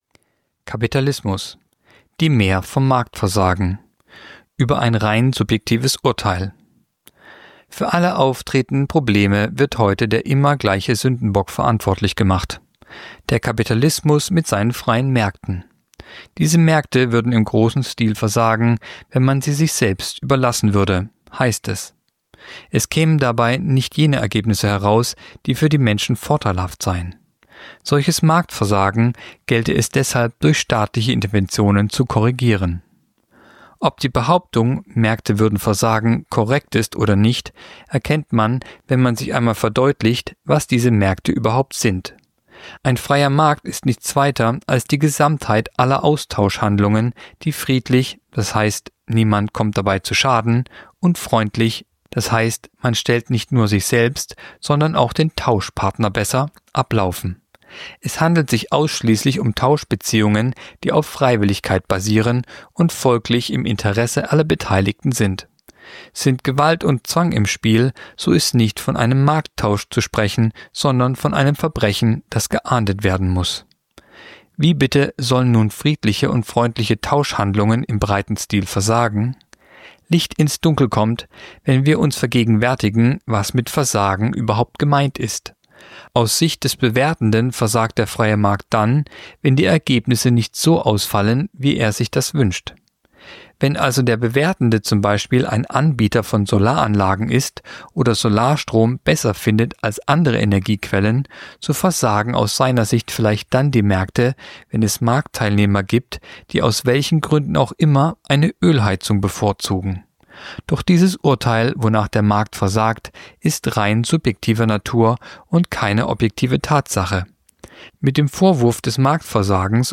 Kolumne der Woche (Radio)Die Mär vom Marktversagen